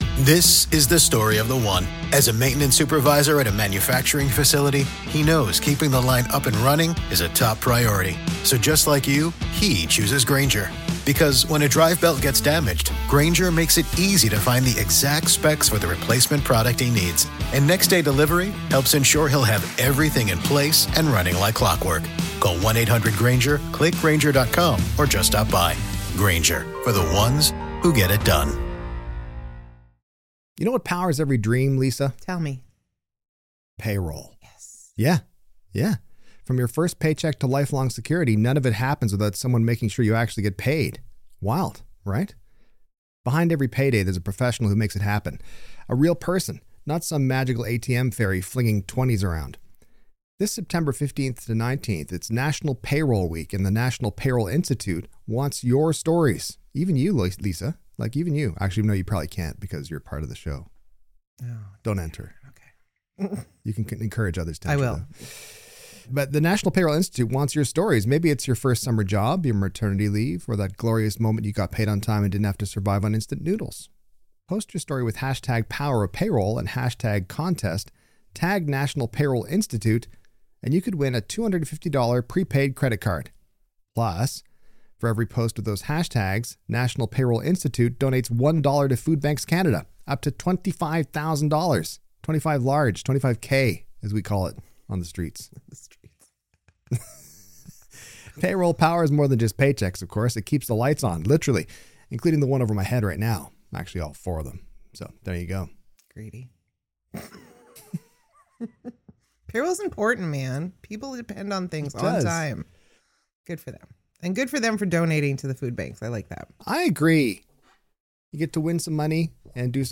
Why listen Because it’s smart, funny, and slightly exasperated—like September in Canada.